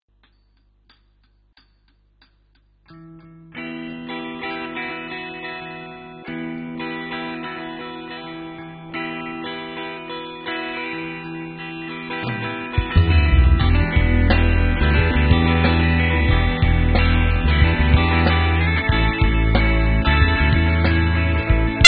los sonidos alternativos americanos como puede ser
el "grunge", nos manifiestan su gusto por artistas como
tienen un sonido muy particular, con una voz
desgarradora que nos deja boquiabiertos en los directos.